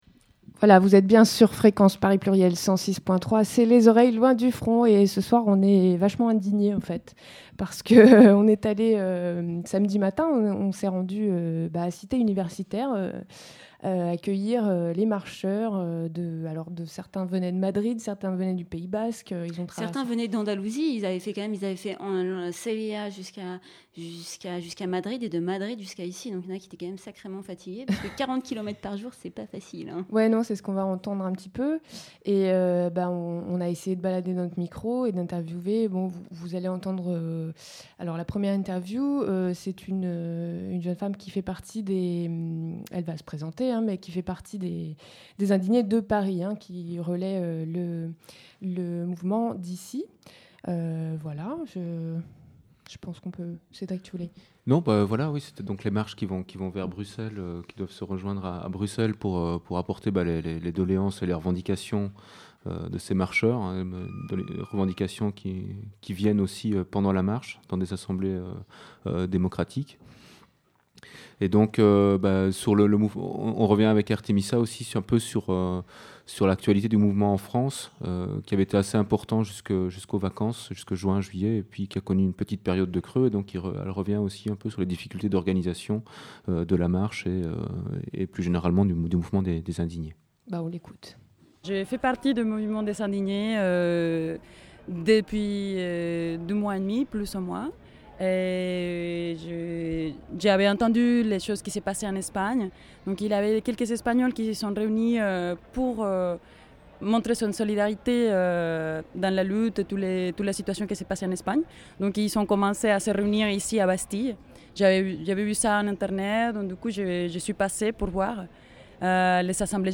On est allé glaner des témoignages à l'arrivée de la marche des indigné-e-s (le 17 septembre 2011) qui fait route sur Bruxelles. On avait aussi en plateau un militant parisien impliqué dans ce mouvement.